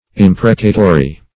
imprecatory - definition of imprecatory - synonyms, pronunciation, spelling from Free Dictionary
Imprecatory \Im"pre*ca*to*ry\, a.